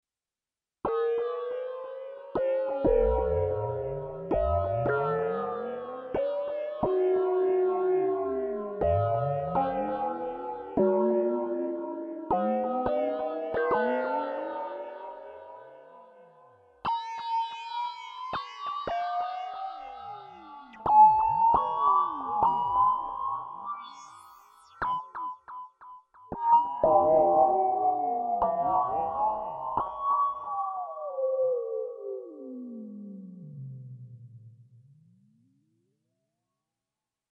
I rimanenti tre campioni stanno a testimoniare proprio questo. con Pad morbidi e sognanti (XioSynth04.mp3), evocative percussioni sintetiche (XioSynth05.mp3) e classici synth bass  (XioSynth06.mp3) lo XioSynth riesce ad esprimersi benissimo in molte tipologie di sonorità sintetiche, con la morbidezza che ormai è marchio di fabbrica in casa Novation.